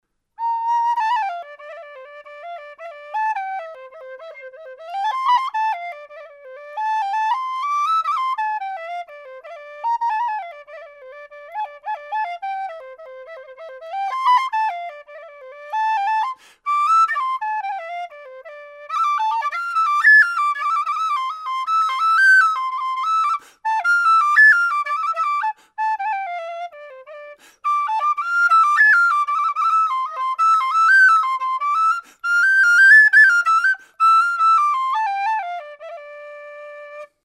C whistle
made out of thin-walled aluminium tubing with 14mm bore